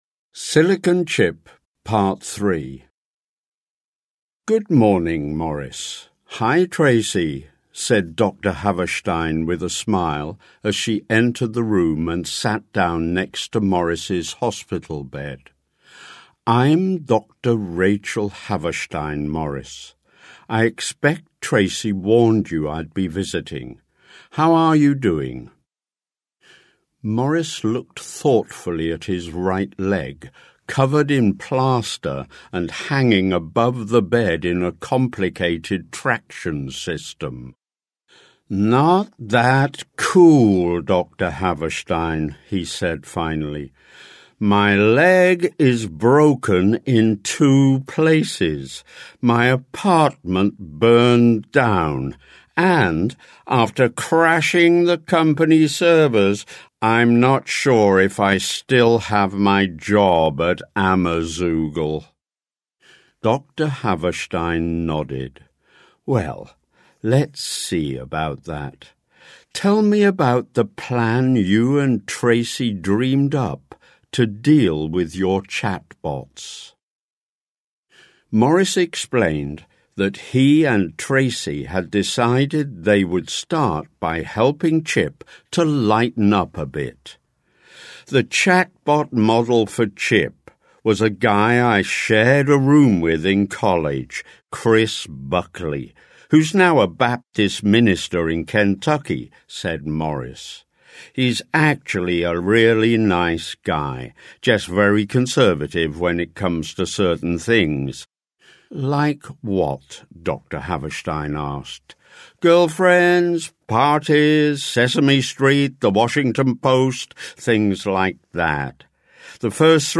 Short Story